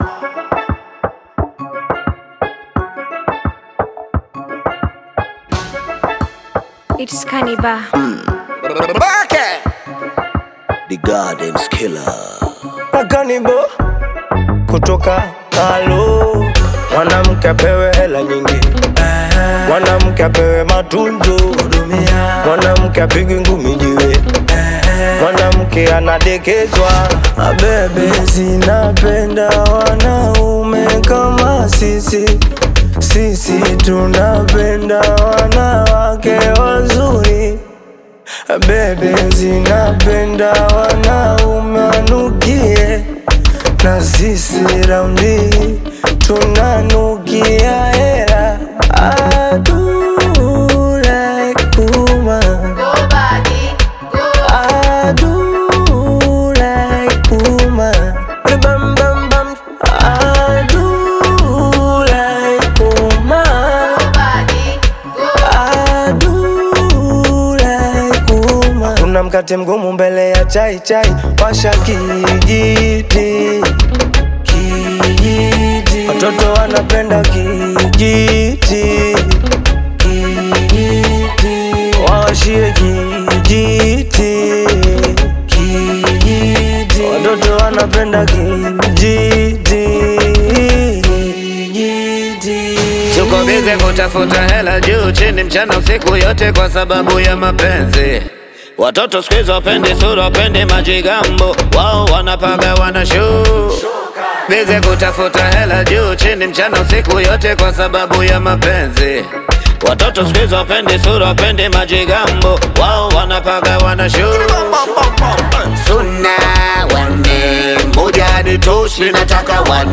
Bongo Flava music track